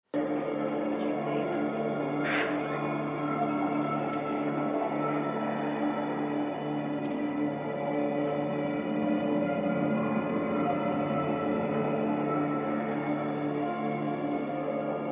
Atmos at The London Experience
60121-atmos-at-the-london-experience.mp3